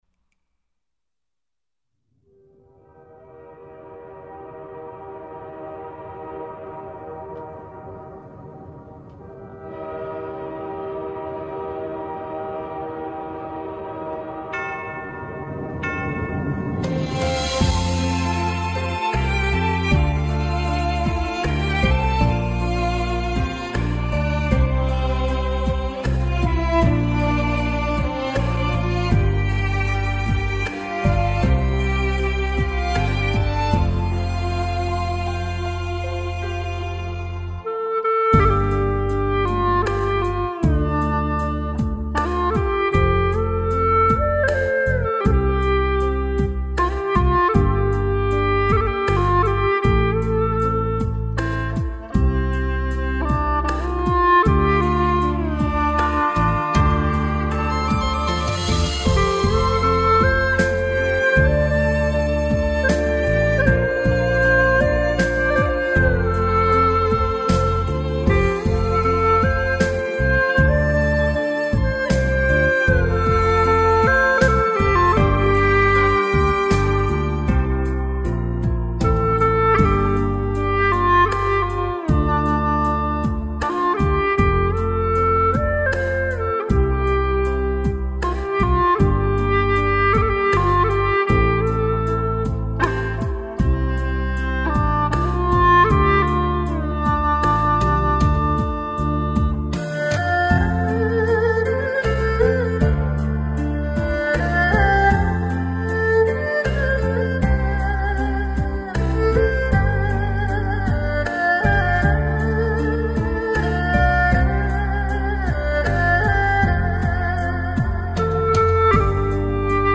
调式 : F 曲类 : 流行